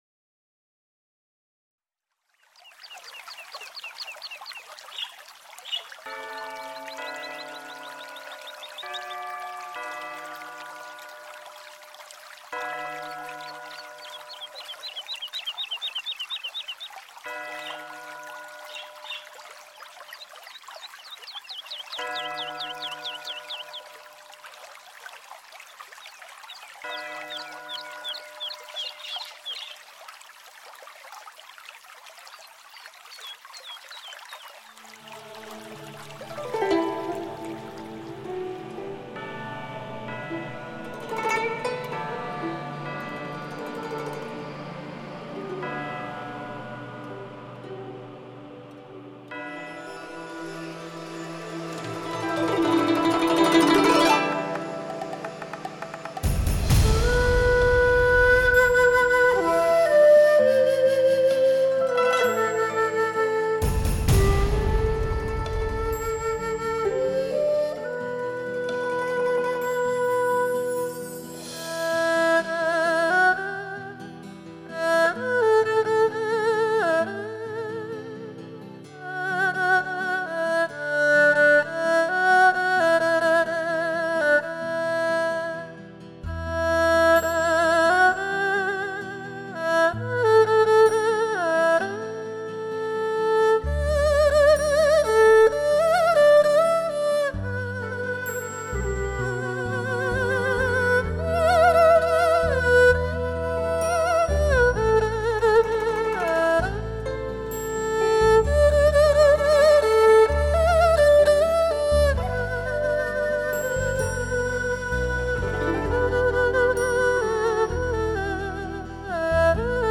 年度民乐精选辑，采用震撼性的DTS-ES6.1环绕声编码技术，使人体会到更真实，更丰富的现场感。
包围感。